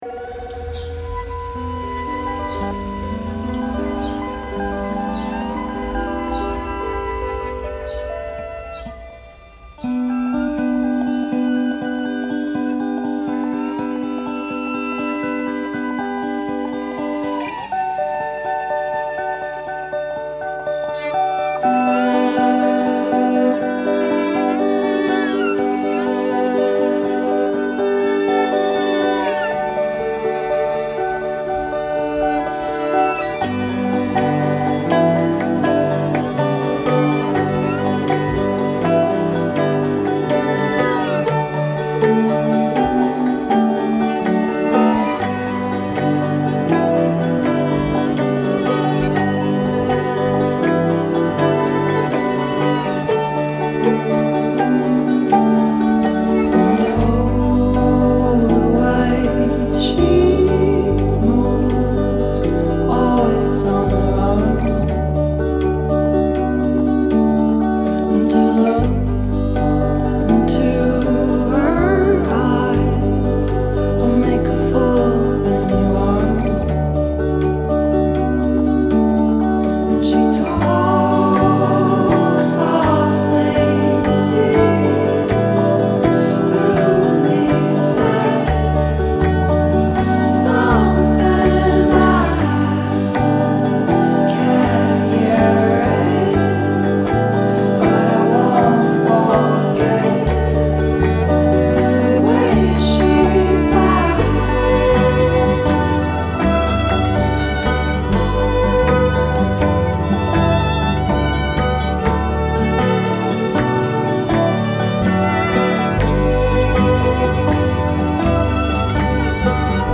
3. The voices that end the song sound like a relieved sigh.
Tags2010s 2013 Eastern US Folk